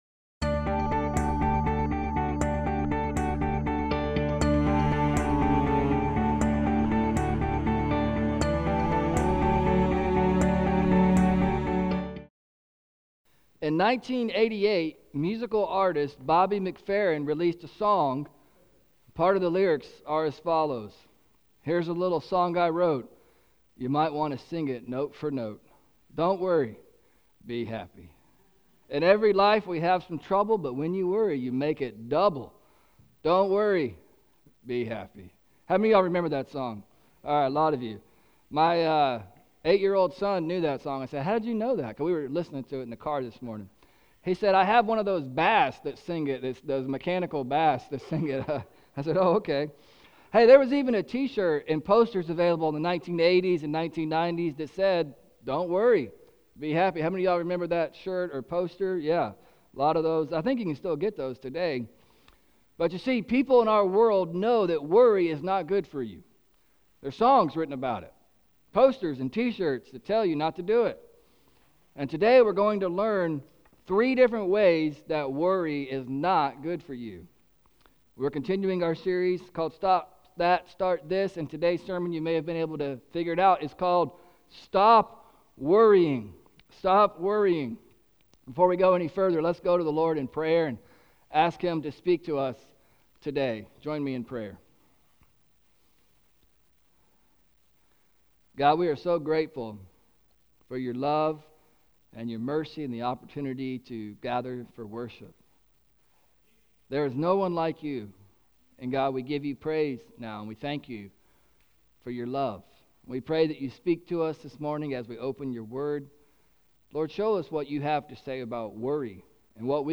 Today’s sermon is called “Stop Worrying!”